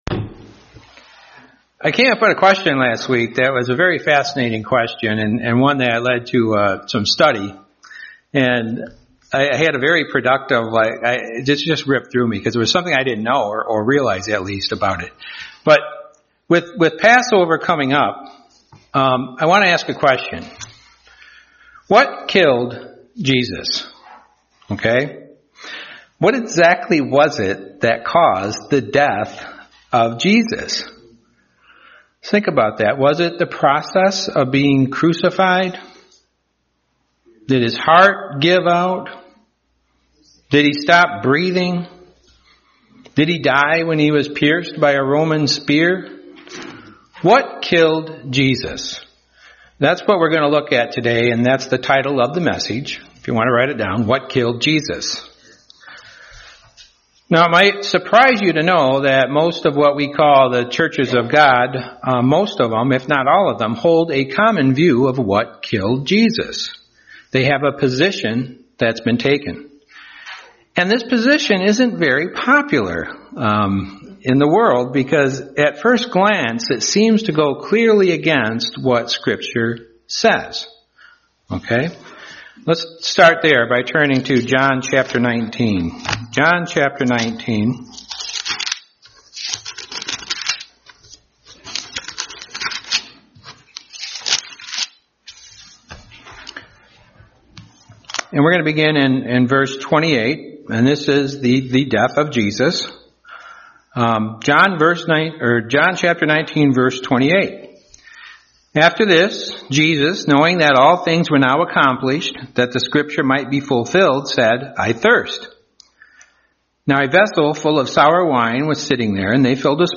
Sermons
Given in Grand Rapids, MI